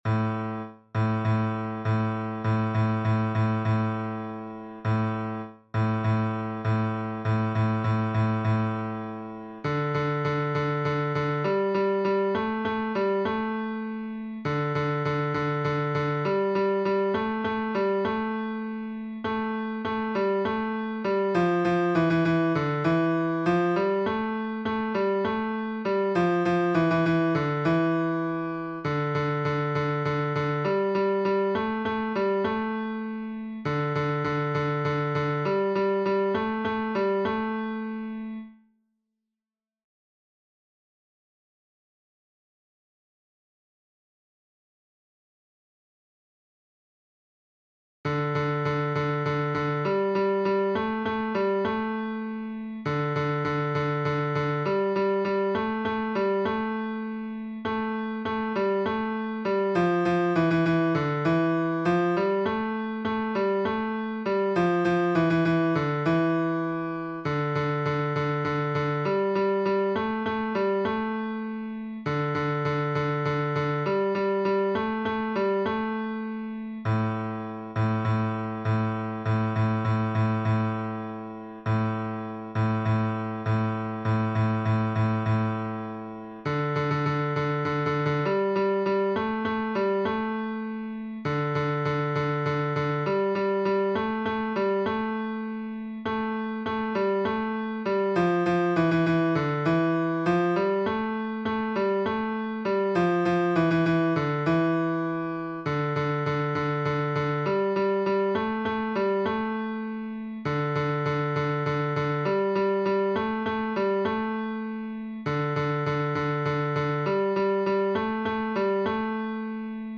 Basse (version piano